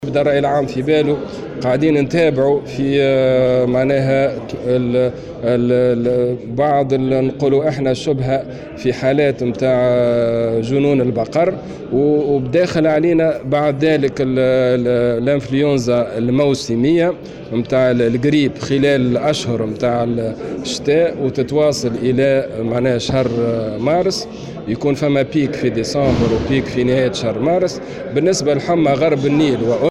كشف وزير الصحة عماد الحمامي اليوم الاثنين عن حالات محتملة يشتبه في اصابتها بمرض جنون البقر في تونس.
وجاءت تصريحاته على هامش انعقاد مجلس جهوي طارئ للصحة بنابل للوقوف على الوضع الصحي بالجهة.